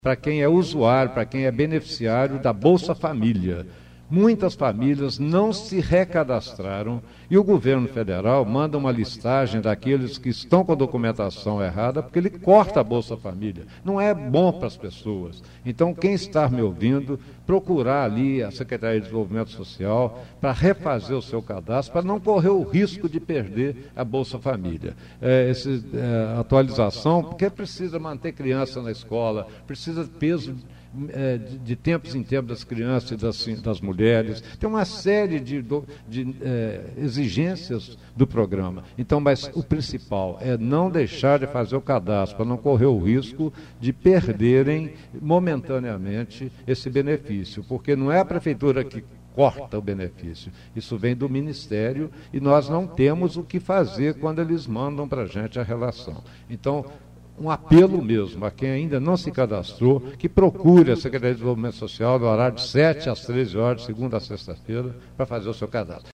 Em entrevista a Rádio Educadora – O Prefeito Edson Teixeira Filho – pediu que as pessoas que são cadastradas no Programa Bolsa Família – que realizem os RE – cadastramento.